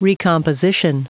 Prononciation du mot recomposition en anglais (fichier audio)
Prononciation du mot : recomposition